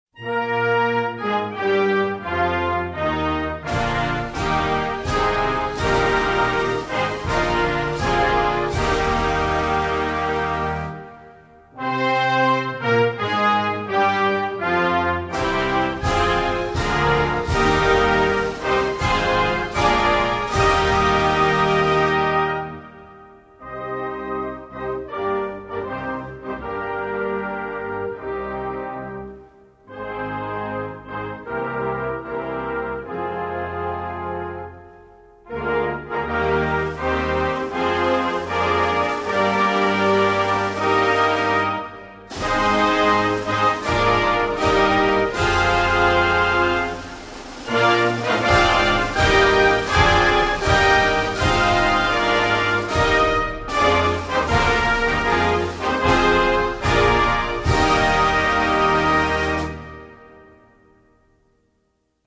Norway_(National_Anthem).mp3